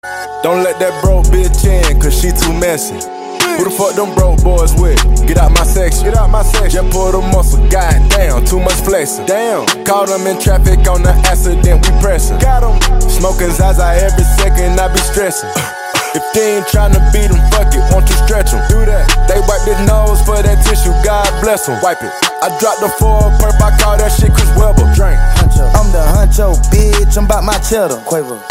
Catégorie: Rap - Hip Hop